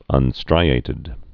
(ŭn-strīātĭd)